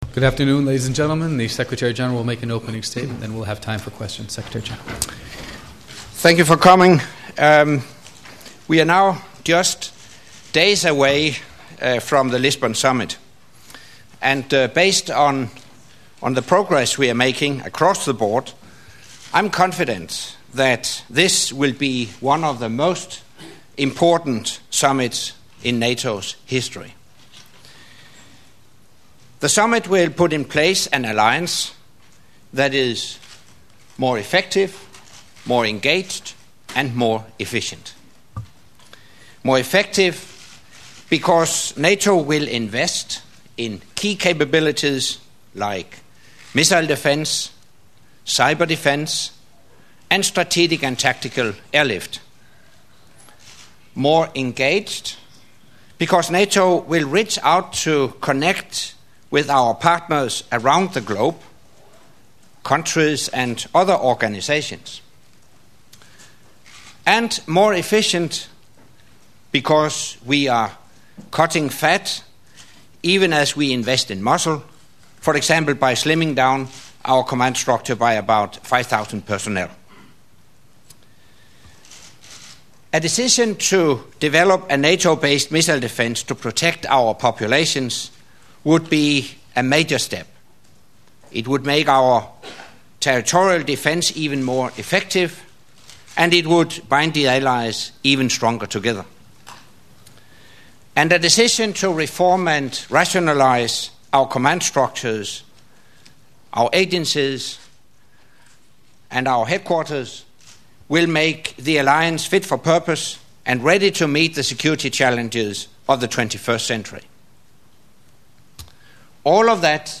Secretary General’s Pre-Summit Press Conference